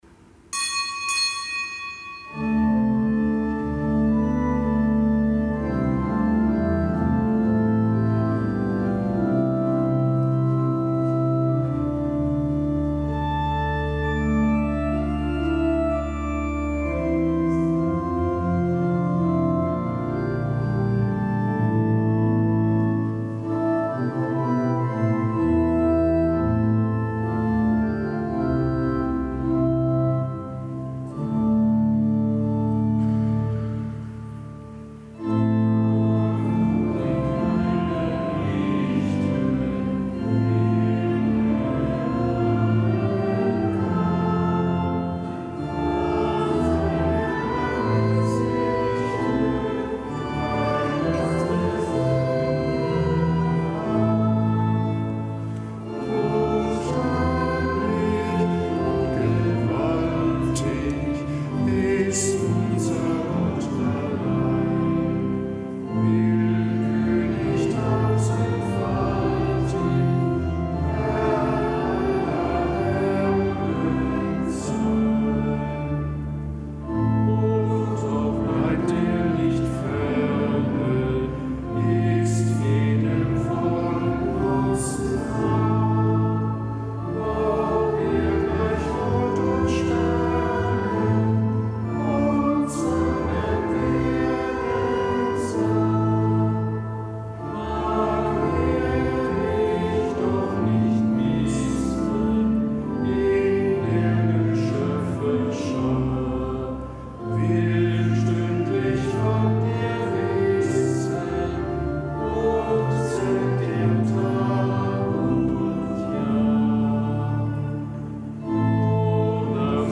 Kapitelsmesse am Gedenktag der heiligen koreanischen Märtyrer
Kapitelsmesse aus dem Kölner Dom am Gedenktag des Heiligen Andreas Kim Taegon, einem Priester und, sowie des heiligen Paul Chong Hasang und dessen Gefährten, die ebenfalls Märtyrer sind.